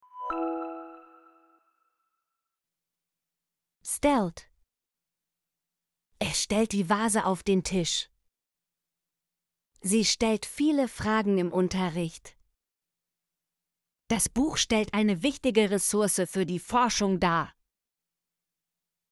stellt - Example Sentences & Pronunciation, German Frequency List